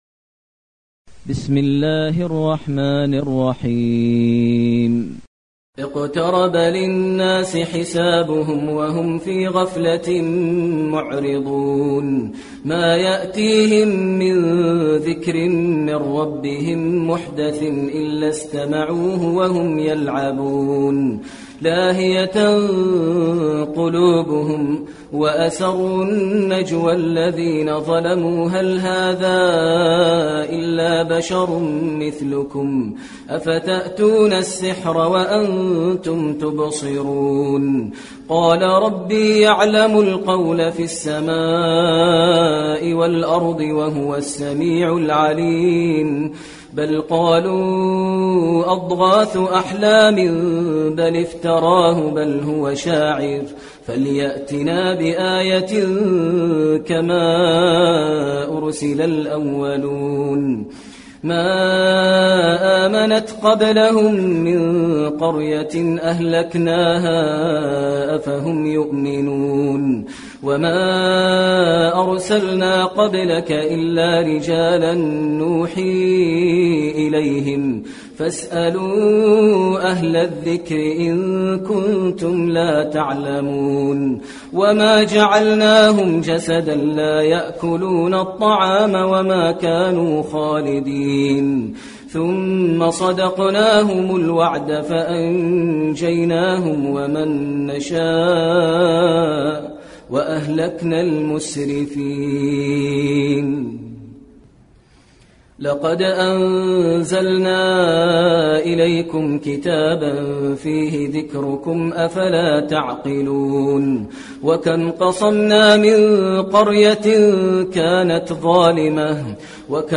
المكان: المسجد النبوي الأنبياء